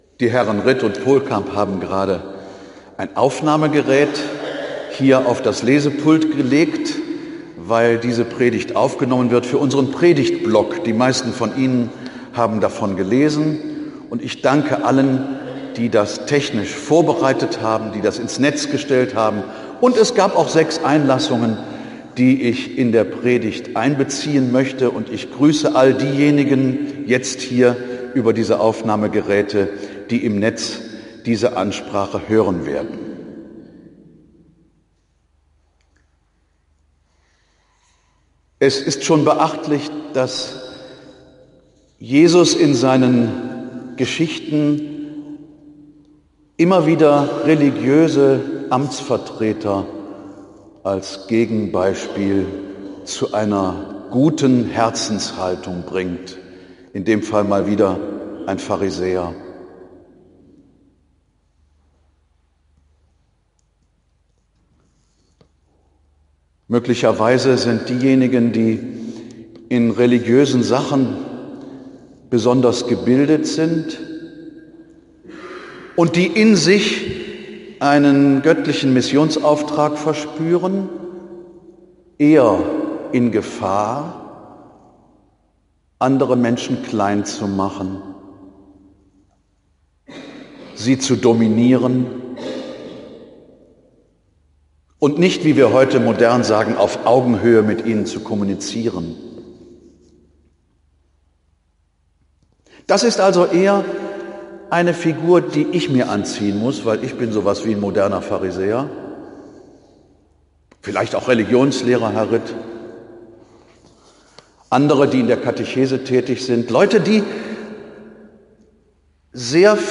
Die Predigt am 27. Oktober: Das Gleichnis vom Pharisäer und vom Zöllner
Am 27. Oktober 18h St. Franziskus